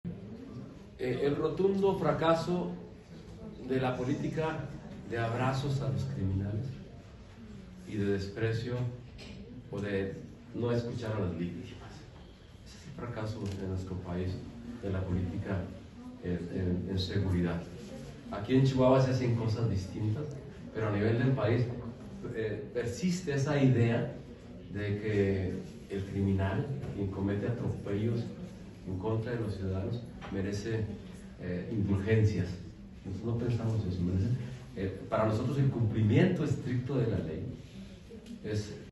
AUDIO: MARIO VÁZQUEZ ROBLES, PRECANDIDATO AL SENADO DE LA REPÚBLICA POR EL FRENTE AMPLIO POR MÉXICO
Durante la rueda de prensa en la que anunció sus aspiraciones al Senado, Vázquez Robles, afirmó que en Chihuahua, a diferencia del Gobierno Federal, los criminales no son tratados con indulgencias .